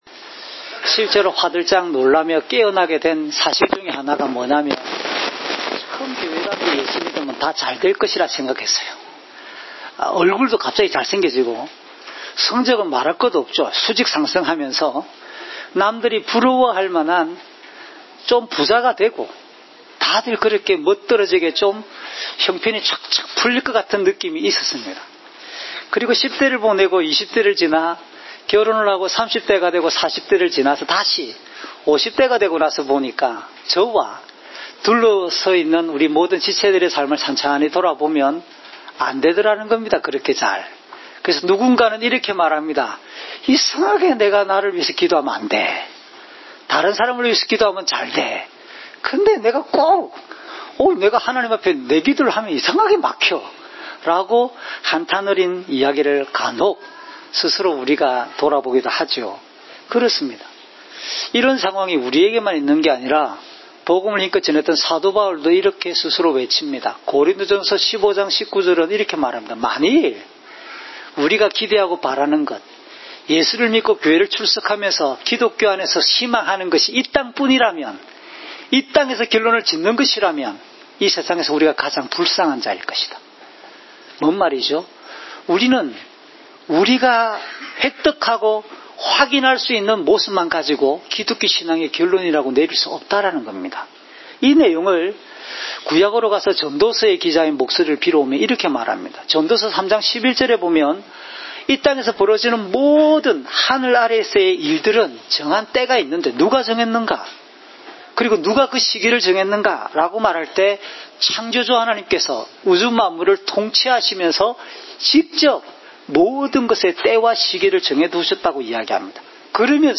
주일설교 - 2019년 12월 8일 “영원한 생명을 주시는 주님을 증언합시다!"(요1:19~34)